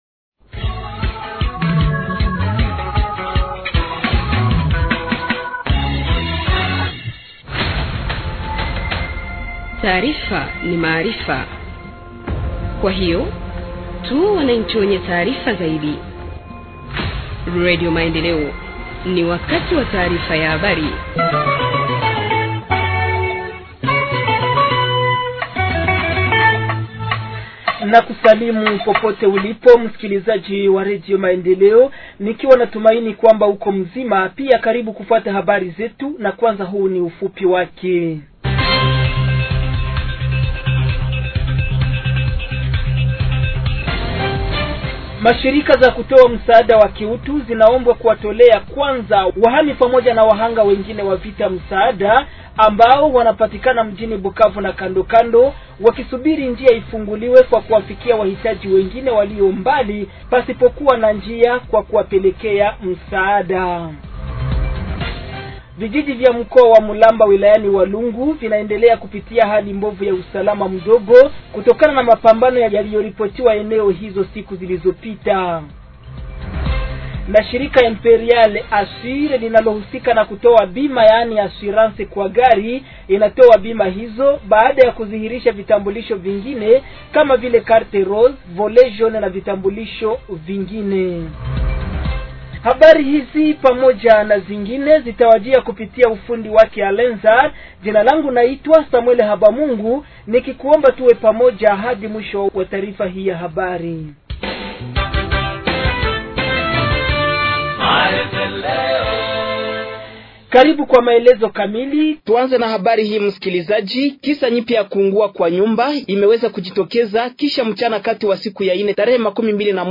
Journal en Swahili du 22 Aout 2025 – Radio Maendeleo